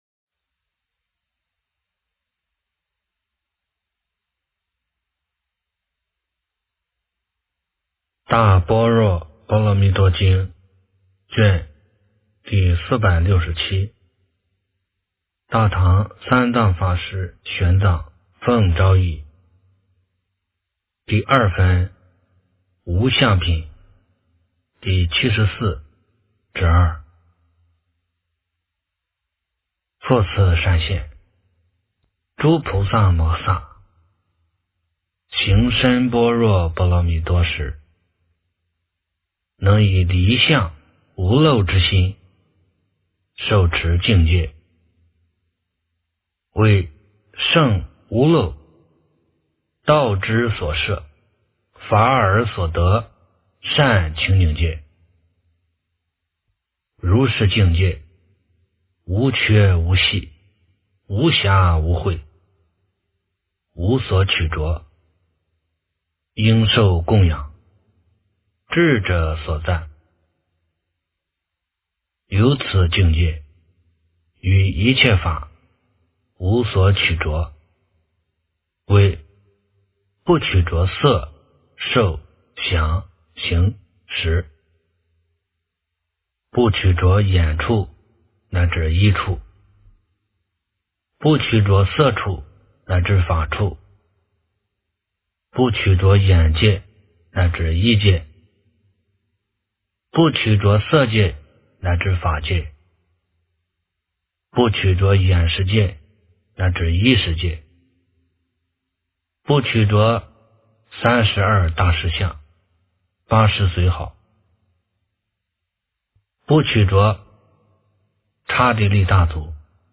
大般若波罗蜜多经第467卷 - 诵经 - 云佛论坛